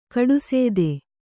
♪ kaḍusēde